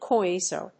coinsure.mp3